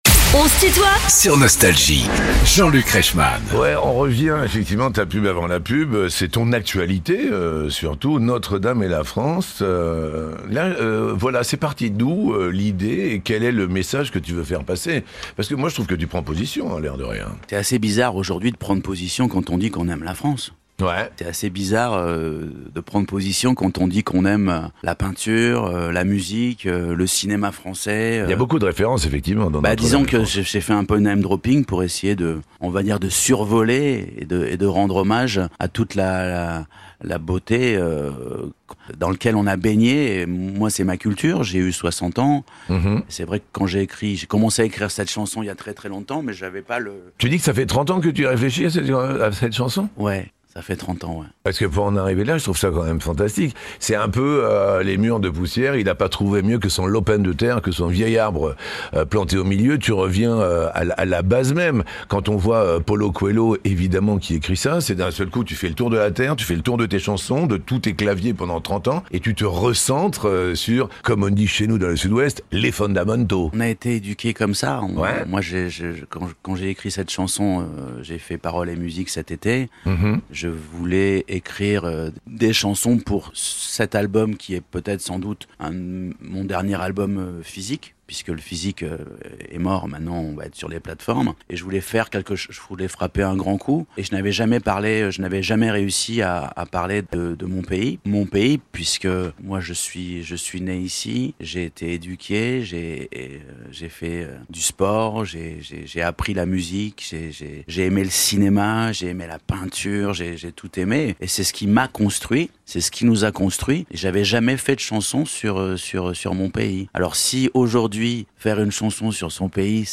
Pascal Obispo est l'invité de Jean-Luc Reichmann dans son émission "On se tutoie ?...", vendredi 21 février de 19h à 20h.